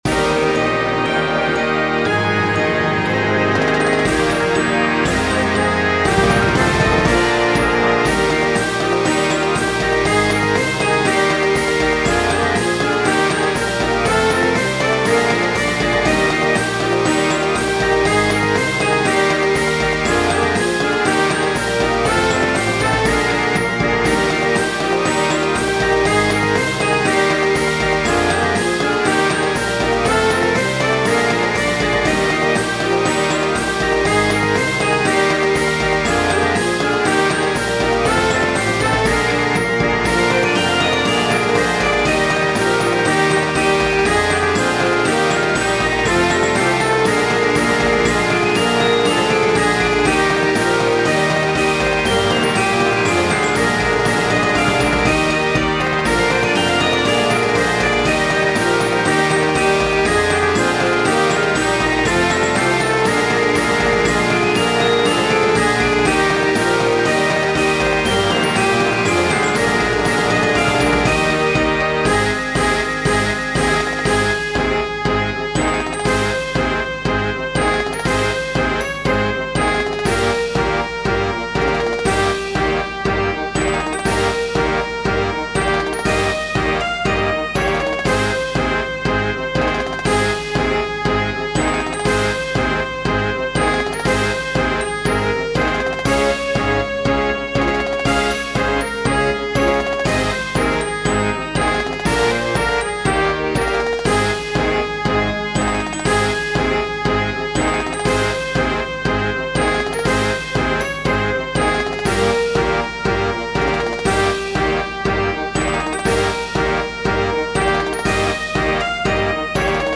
The Puma (Marching Band Arrangement)
and remade into a song for marching band.